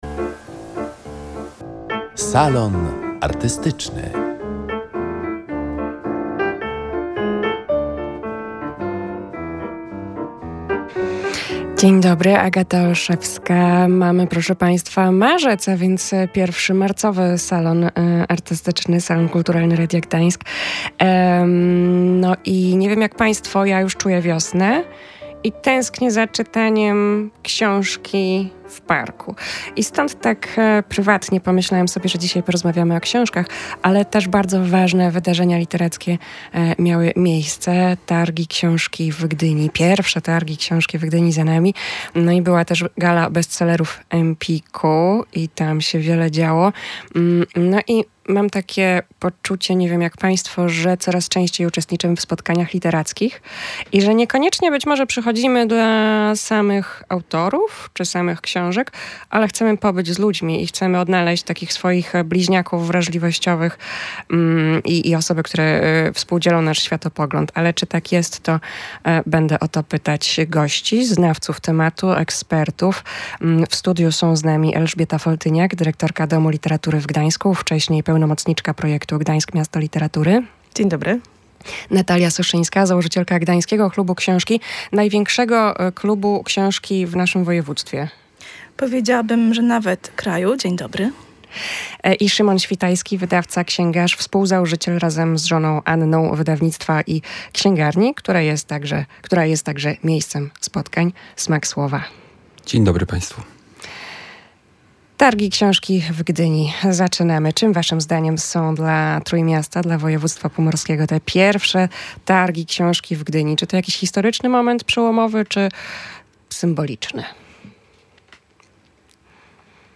eksperci, prezentujący różne perspektywy: instytucjonalną, wydawniczo-księgarską i czytelniczą.